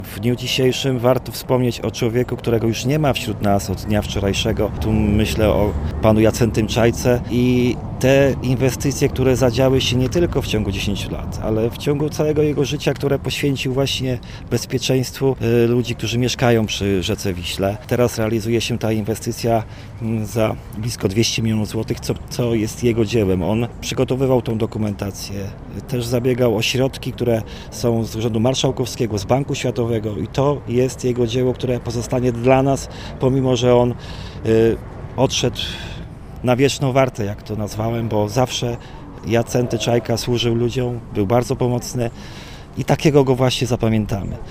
Starosta sandomierski Marcin Piwnik, wspominając zmarłego, podkreśla jego zasługi w ochronie przeciwpowodziowej powiatu: